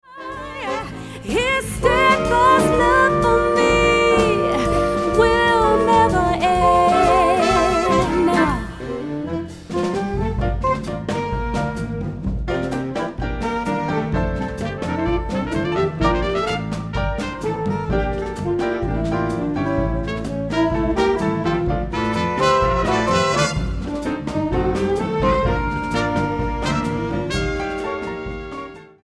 STYLE: Jazz